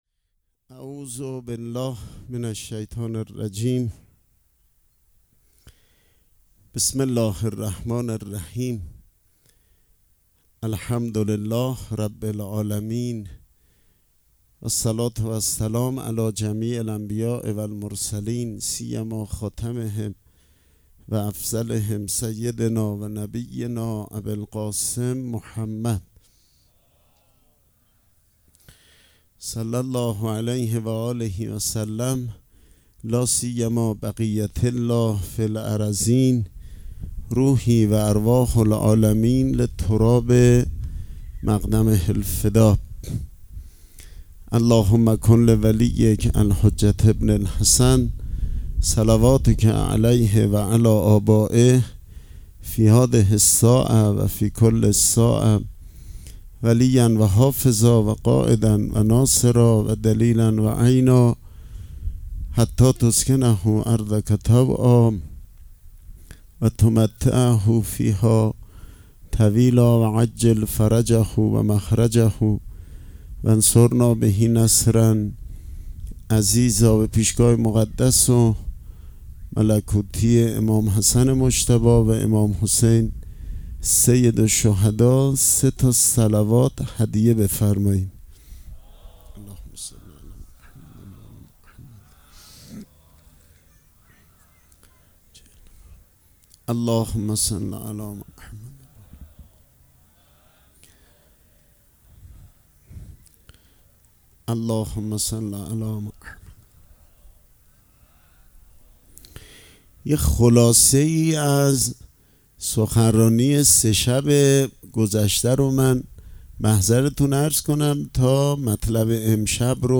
صوت شب چهارم محرم